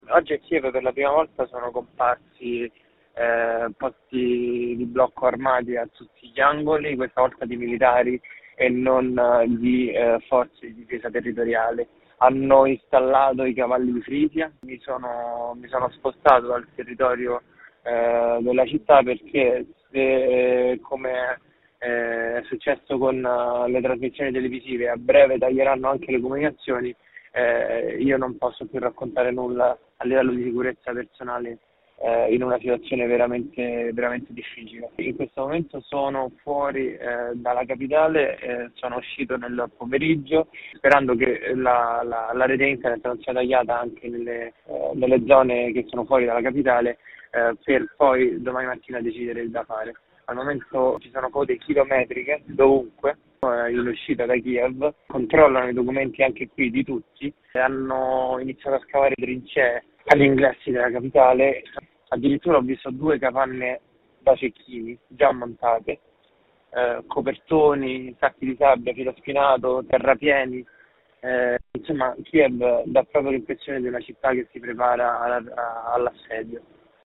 Il racconto della giornata di martedì 1 marzo 2022 con le notizie principali del giornale radio delle 19.30. Nel sesto giorno di guerra la Russia ha intensificato i bombardamenti sulle città.